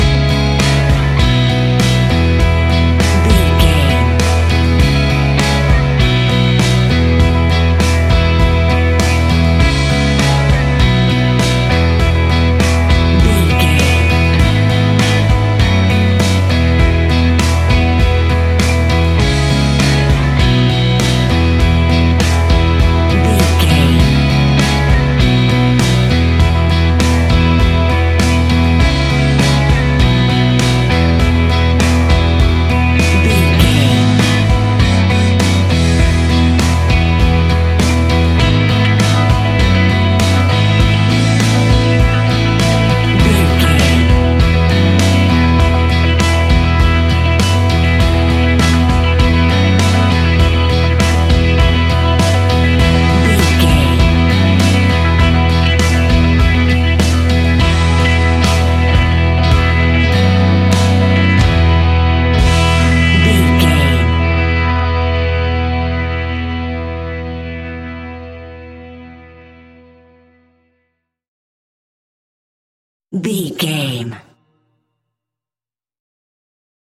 Ionian/Major
indie pop
fun
energetic
uplifting
upbeat
groovy
guitars
bass
drums
piano
organ